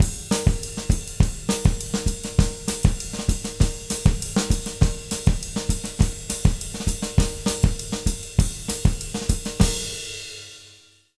100SONGO04-L.wav